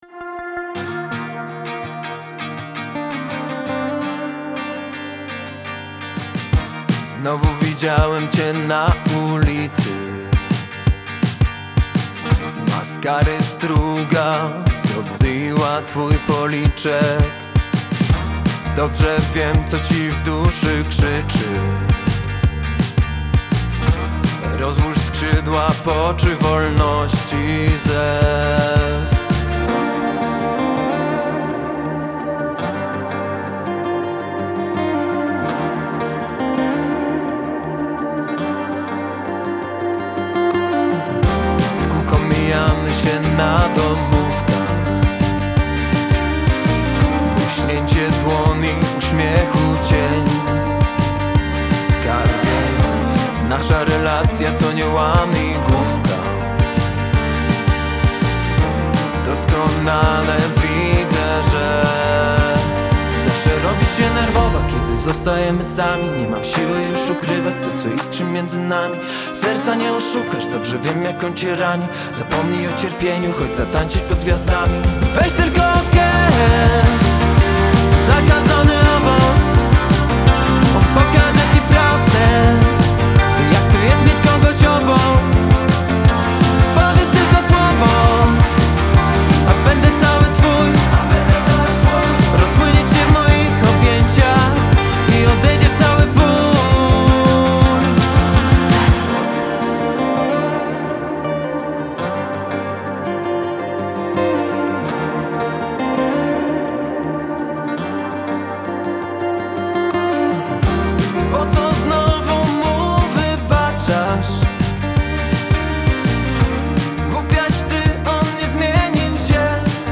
Singiel (Radio)